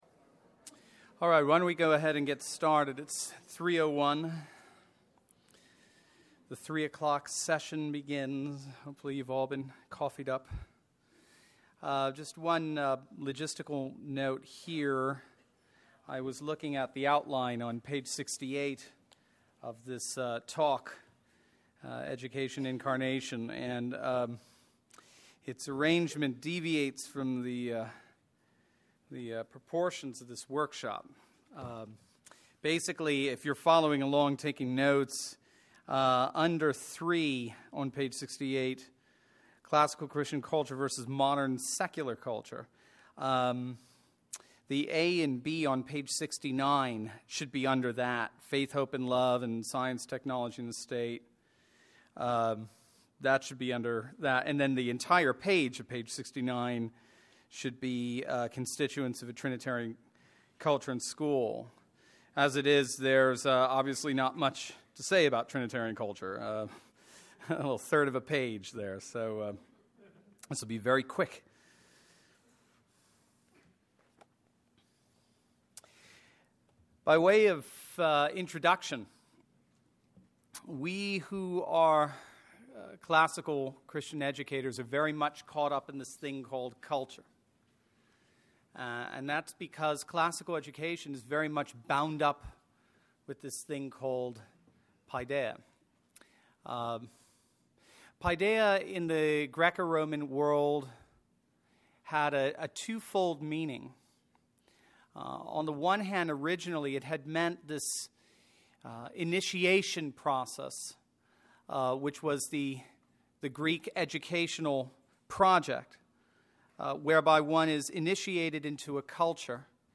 2012 Workshop Talk | 1:14:52 | All Grade Levels, Culture & Faith, Virtue, Character, Discipline